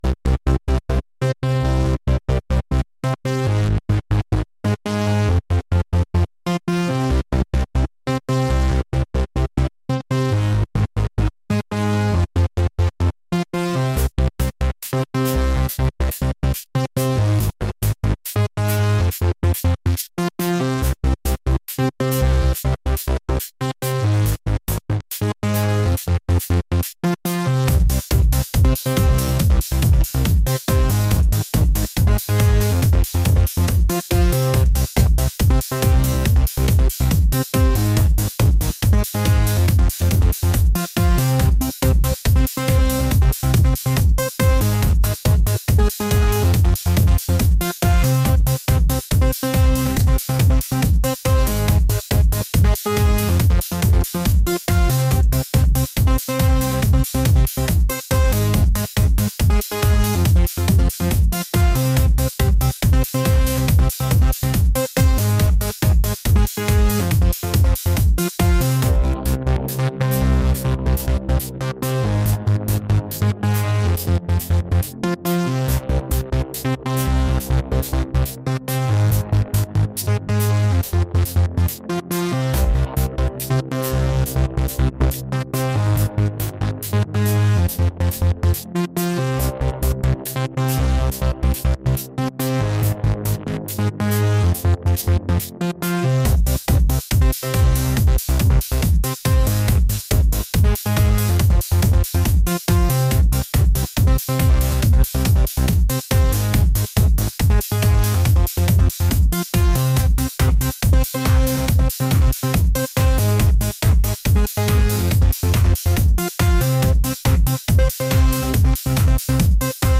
electronic | energetic